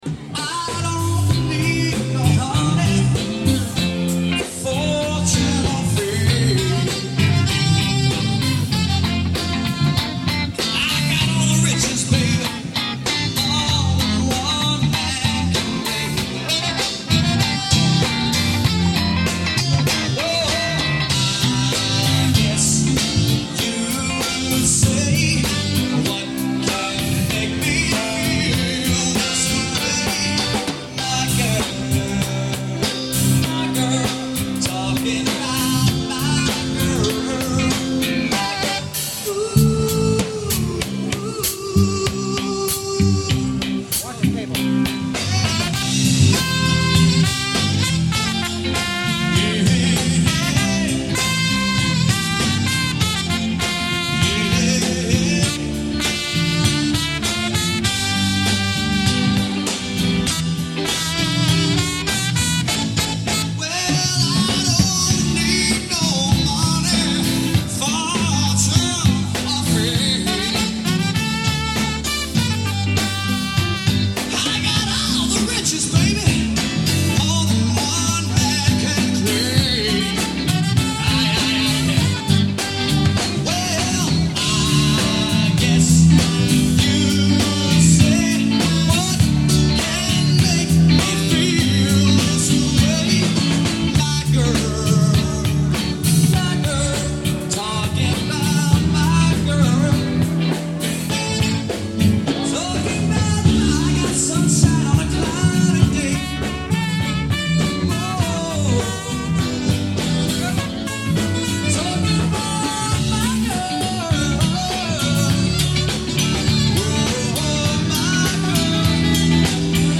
Some recordings may be clearer than others.
American Dance